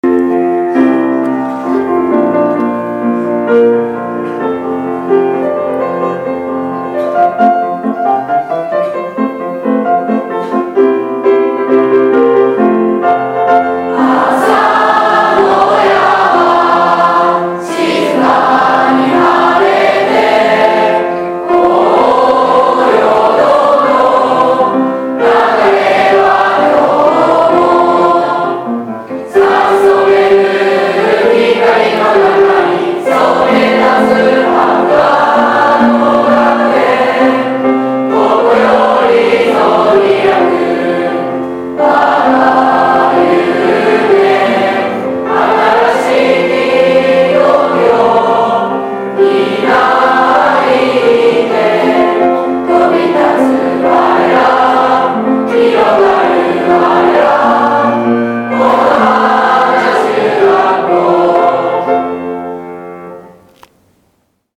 卒業生が作成した、校歌が掲げられた体育館で、
１・２年生の校歌斉唱から修了式は始まりました。
５３期・５４期生による校歌斉唱